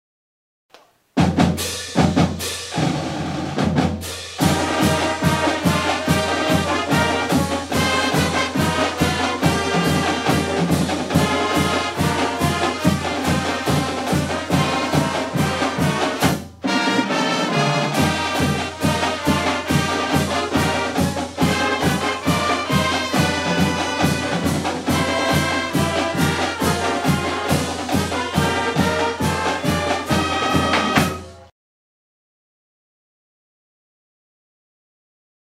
South Point Marching Band Website